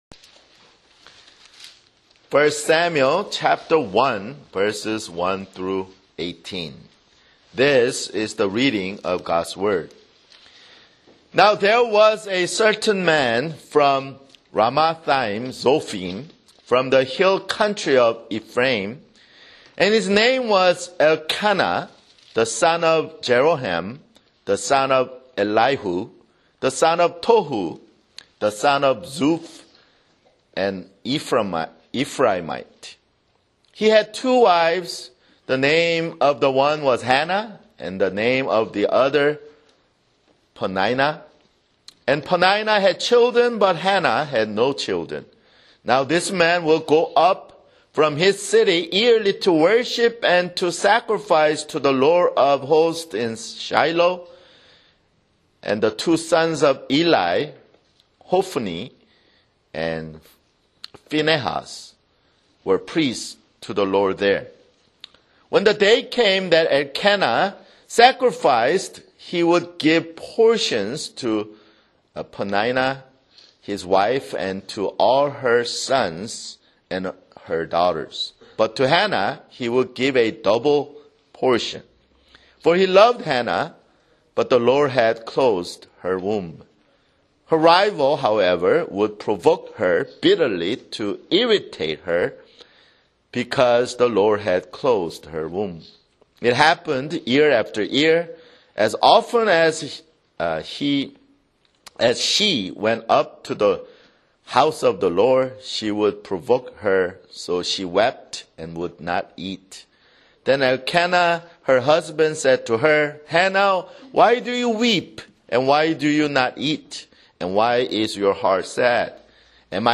[Sermon] 1 Samuel (4)